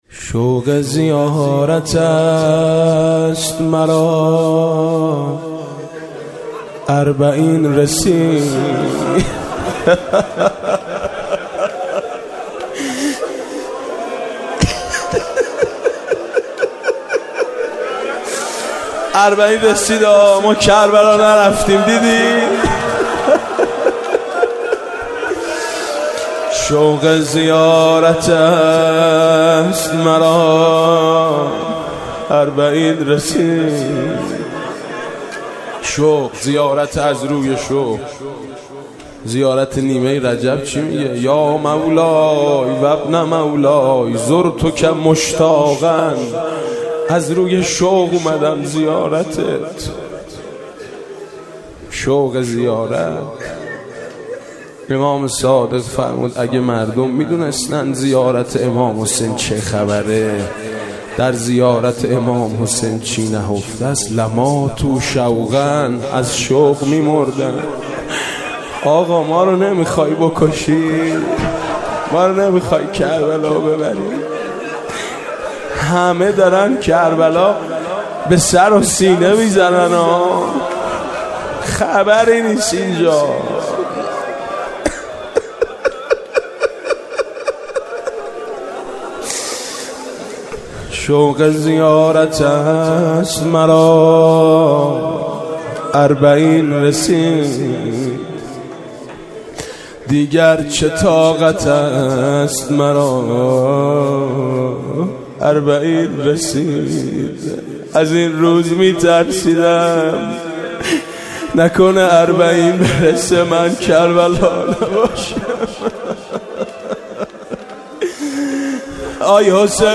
مناسبت : اربعین حسینی
مداح : میثم مطیعی قالب : روضه